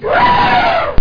ELEPHANT.mp3